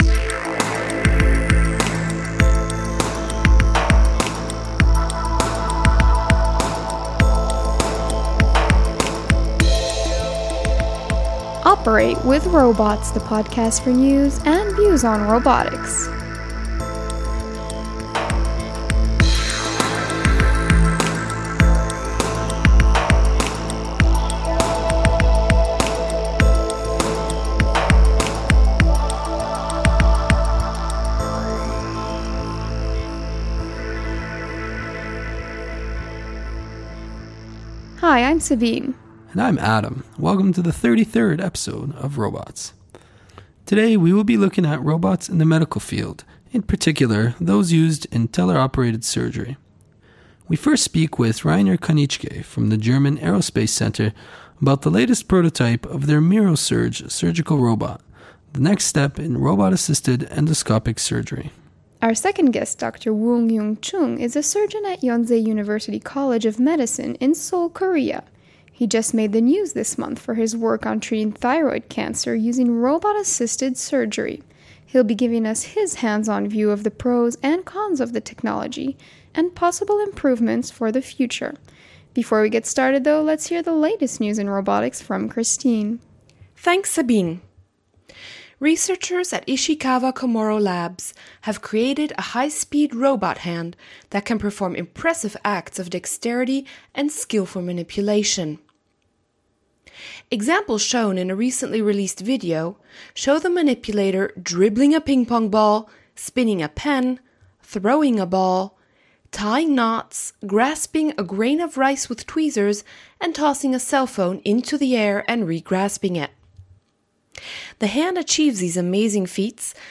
He then wraps up the interview by speaking about soft actuators that allow surgeons to move the robot arms around manually with as much effort as moving a feather.